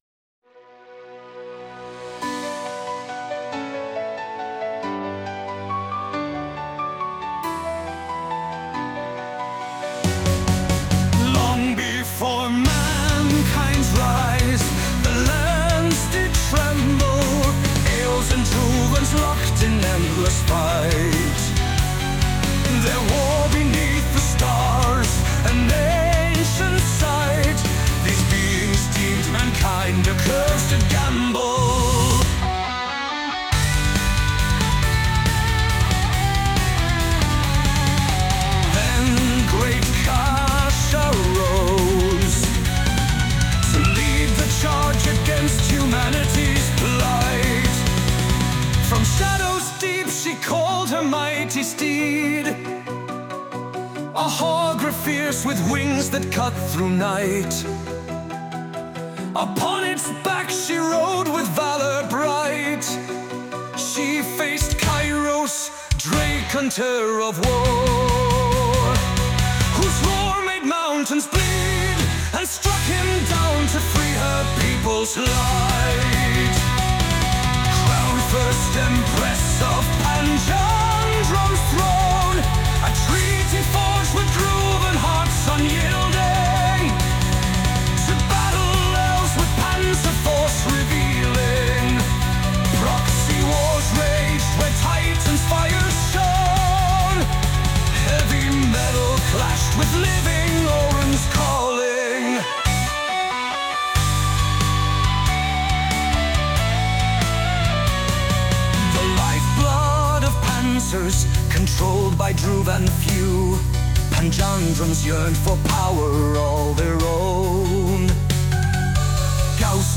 ...and perform a shocking ballad of the Panjandran Empire!